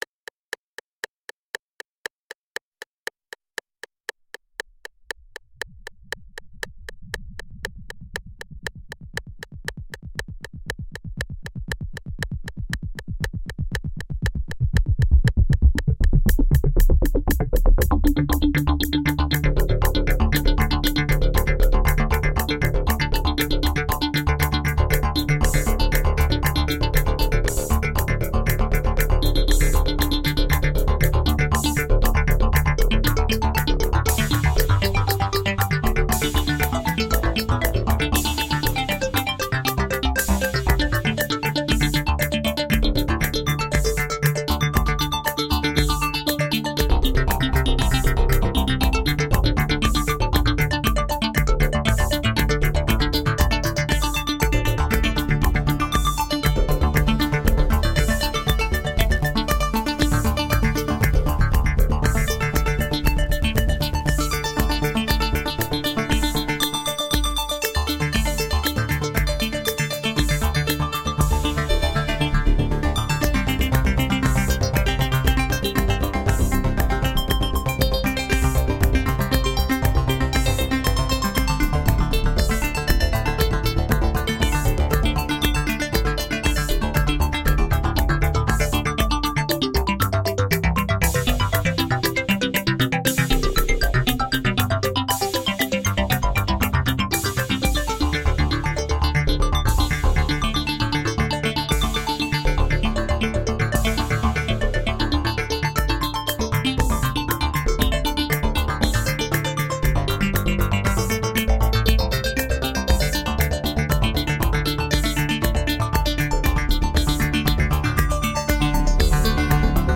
Tagged as: Electronica, Other